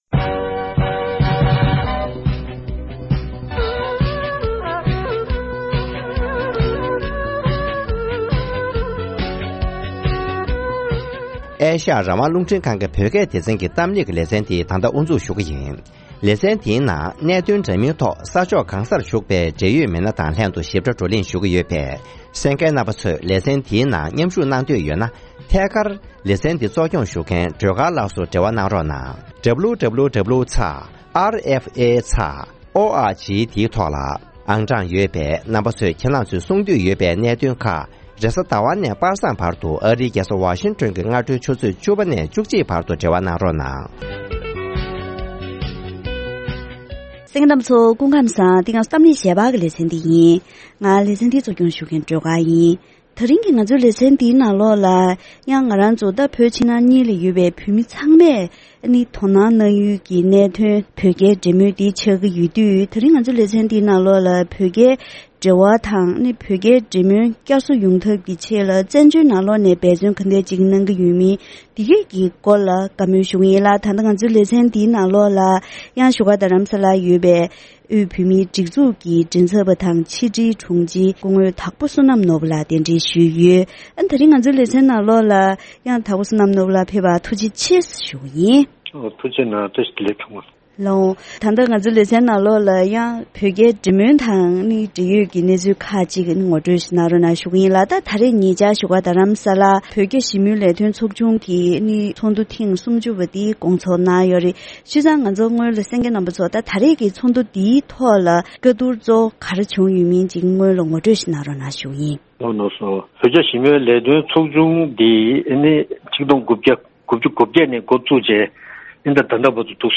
བོད་རྒྱ་ཞི་མོལ་ལས་དོན་ཚོགས་ཆུང་ཞེས་པ་གསར་བཙུགས་གནང་བ་ནས་ད་ལྟ་བར་ལས་དོན་སྤེལ་ཕྱོགས་སོགས་དང་འབྲེལ་བའི་ཐད་གླེང་མོལ་ཞུས་པ།